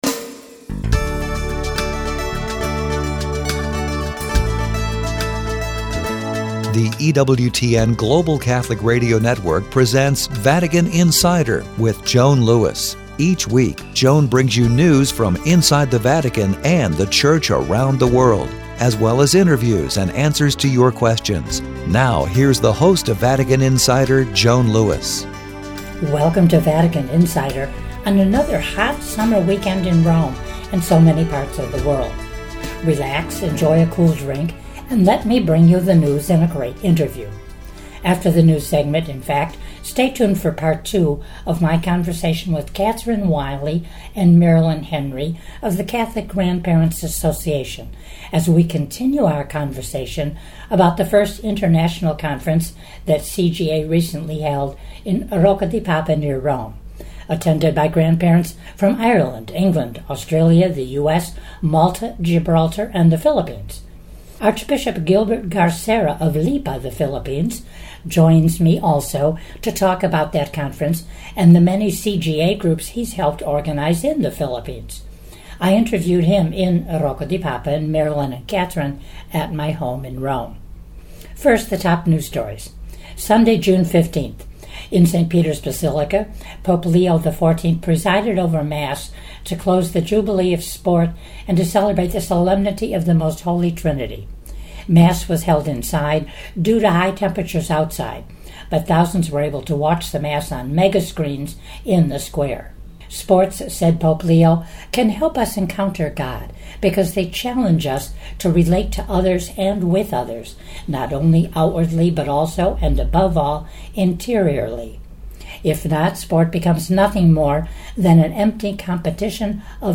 Relax, enjoy a cool drink and let me bring you the news and a great interview!After the news segment